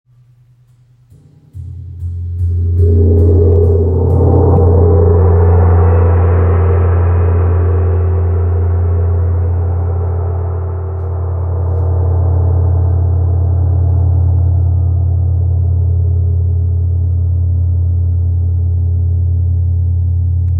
Large Gong with Om Design – 67cm
Every strike produces deep, resonant tones that ripple through the body and mind, fostering clarity, balance, and profound relaxation.
Its impressive size allows for a powerful and immersive sound experience, making it ideal for sound baths, meditation sessions, or energy healing.
Gong2.mp3